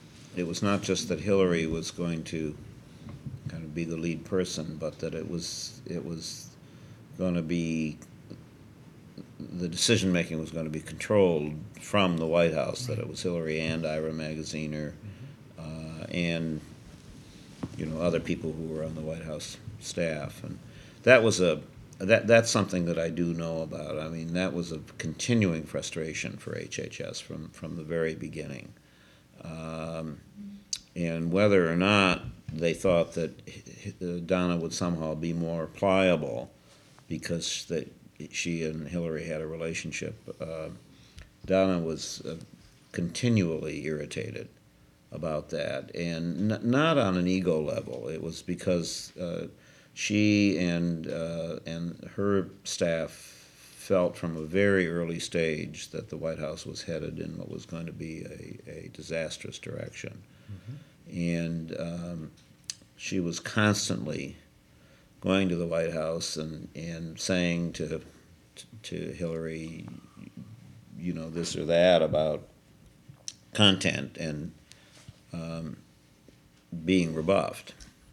Date: May 24, 2004 Participants Peter Edelman Associated Resources Peter Edelman Oral History The Bill Clinton Presidential History Project Audio File Transcript